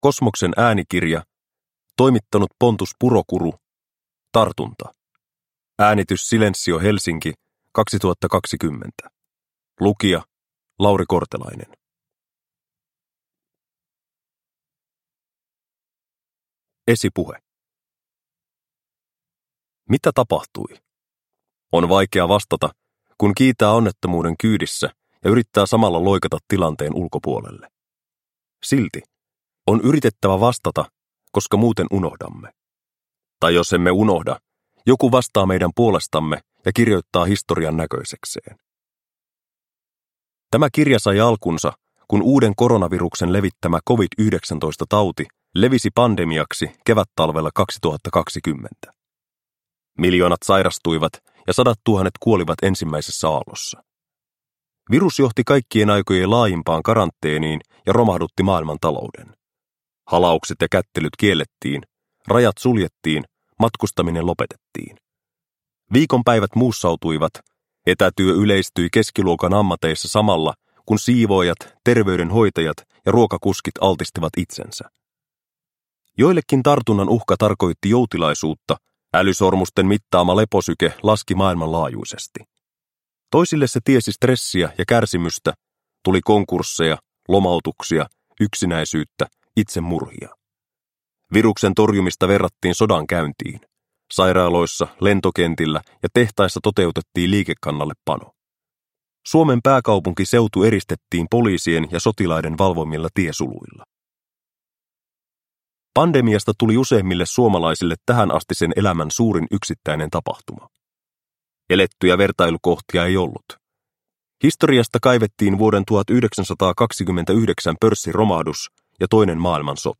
Tartunta – Ljudbok